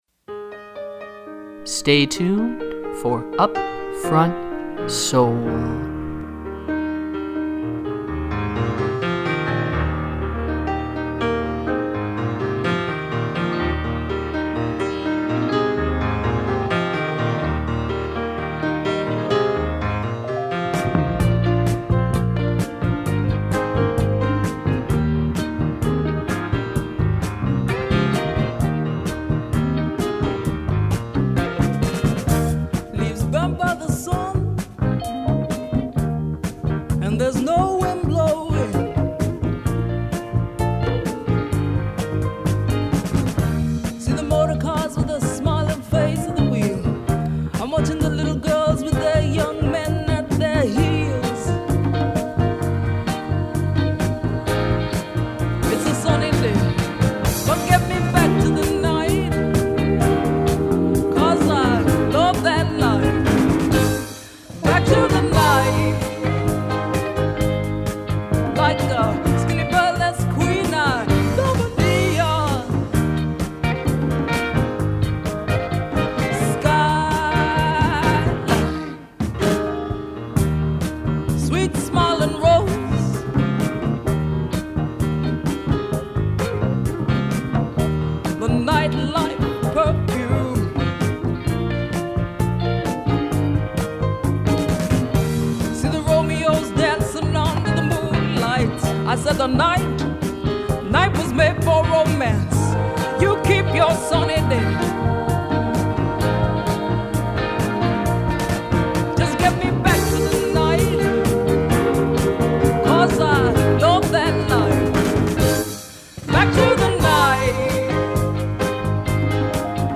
Funk, Soul & Jazz 120 Minutes of Soulful Sounds to Which You May Get Down